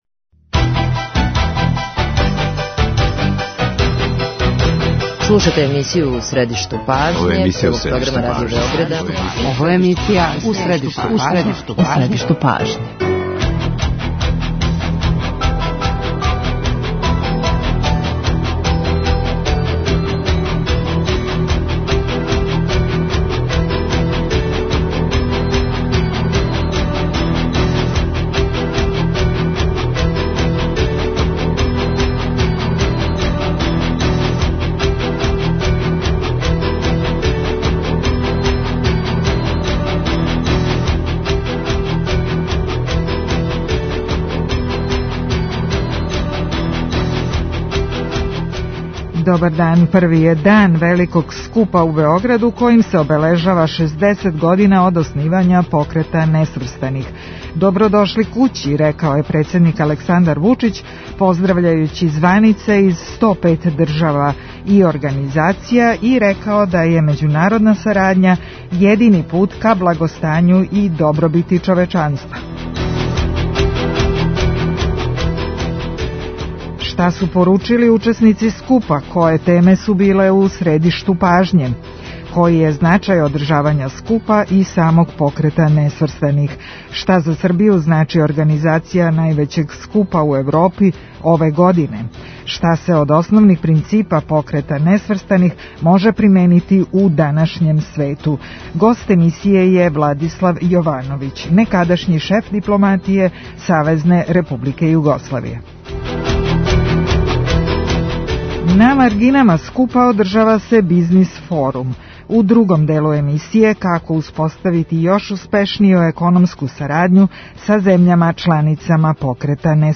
Свакога радног дана емисија "У средишту пажње" доноси интервју са нашим најбољим аналитичарима и коментаторима, политичарима и експертима, друштвеним иноваторима и другим познатим личностима, или личностима које ће убрзо постати познате.
Шта се од основних принципа Покрета несврстаних може применити у данашњем свету? Гост емисије је Владислав Јовановић, некадашњи шеф дипломатије СРЈ.